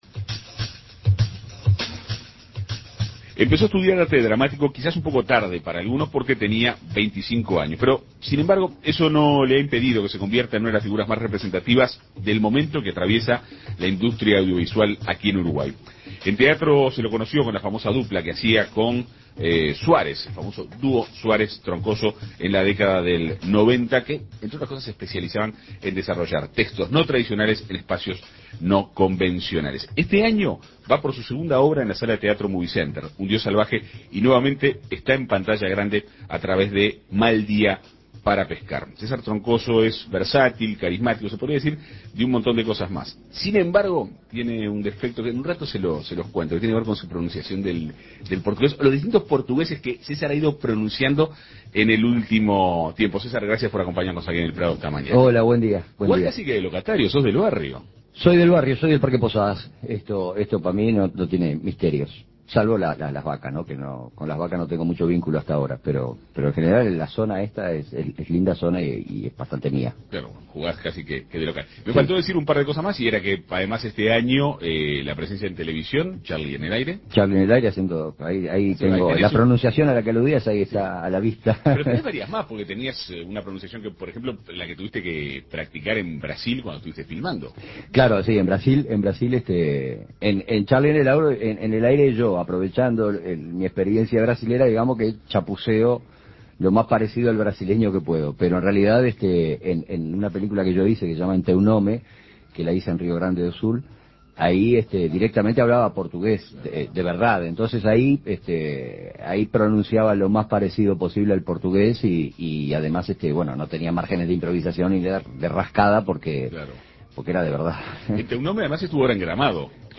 Además, forma parte del elenco de la premiada película uruguaya "Mal día para pescar" y encarna a un particular pastor en "Charly en el aire", la nueva ficción de Canal 4. En Perspectiva Segunda Mañana dialogó con este polifacético artista para conocer en detalle sus proyectos.